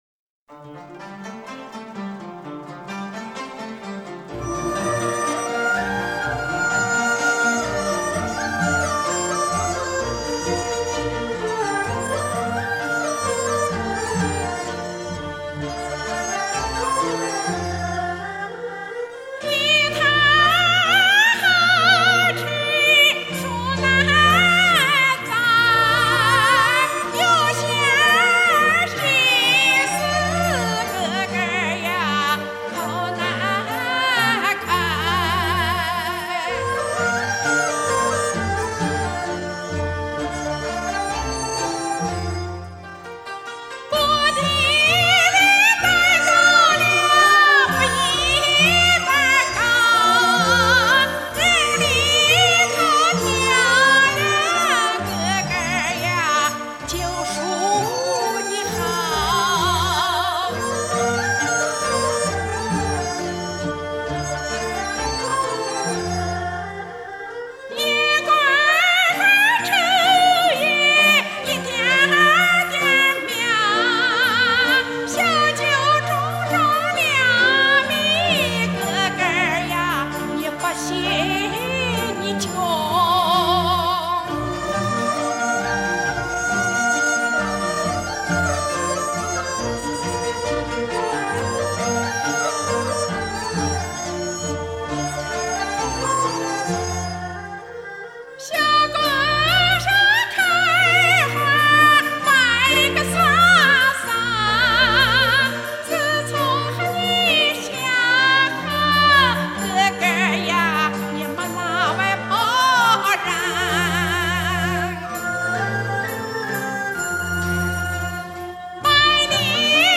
左权民歌